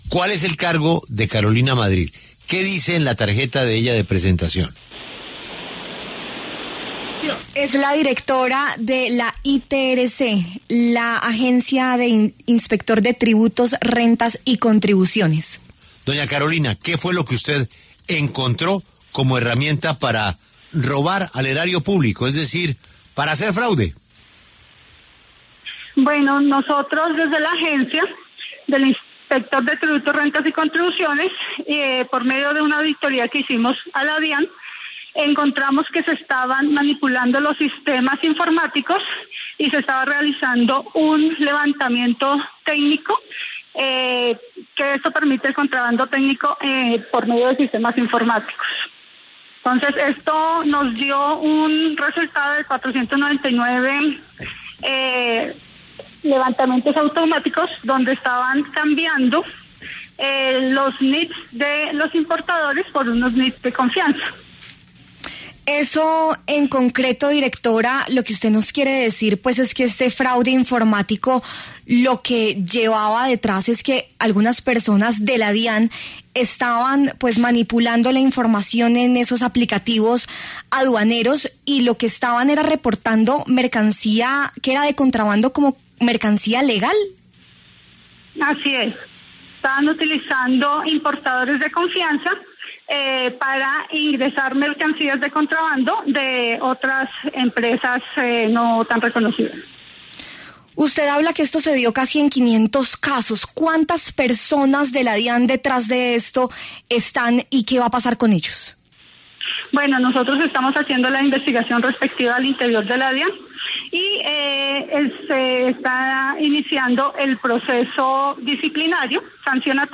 Eva Carolina Madrid, directora de la ITRC, habló en La W sobre el caso de posible fraude aduanero en la entidad.